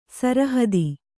♪ sarahadi